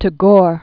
(tə-gôr, tä-), Sir Rabindranath 1861-1941.